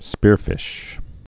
(spîrfĭsh)